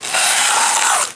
MONSTER_Noise_03_mono.wav